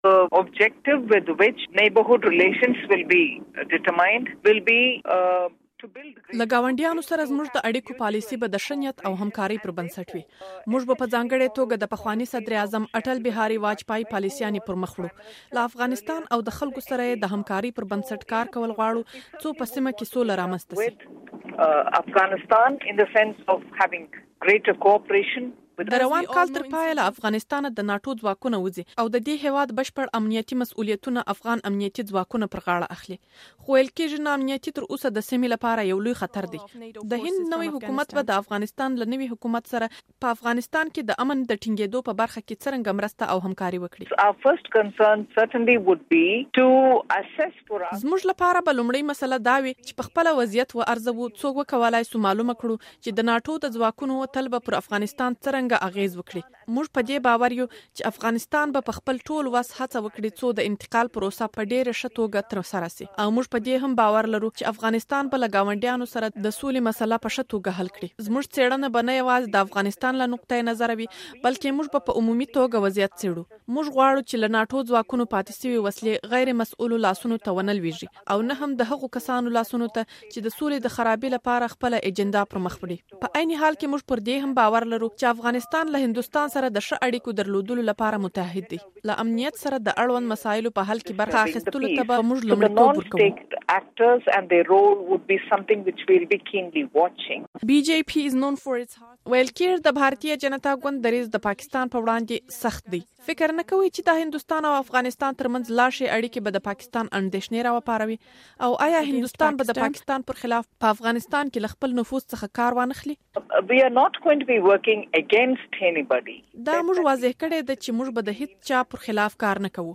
له نرملا سيتهارامن سره مرکه